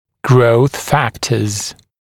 [grəuθ ‘fæktəz][гроус ‘фэктэз]факторы роста